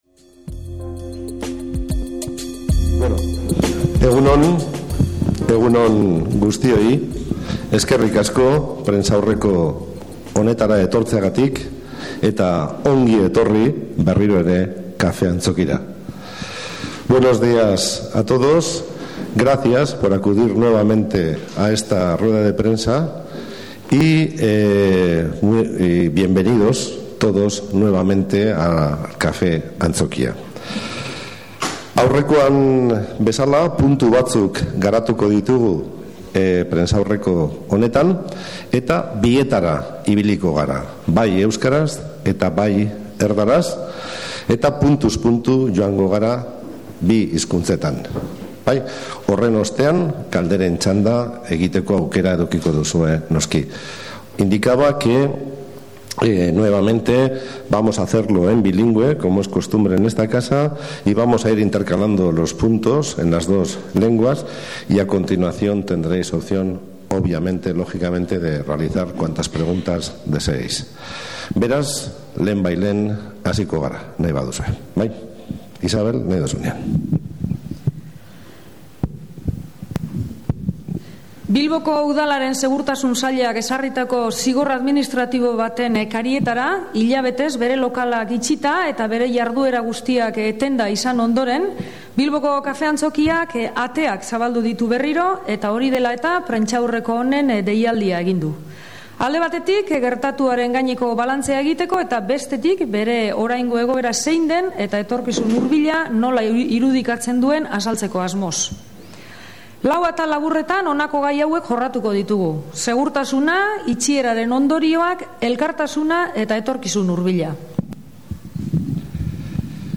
solasaldia
Bide batez, Kafe Antzokiko arduradunek prentsaurrekoa eman dute, hedabide askoren aurrean. Bertan lau gai jorratu dituzte: segurtasuna, itxieraren ondorioak, elkartasunaren uholdea eta etorkizun hurbila. Eskegita dagoen dokumentuan prentsaurreko guztia dago (euskaraz eta gazteleraz eginikoa) baita kazetariek egindako galderak ere.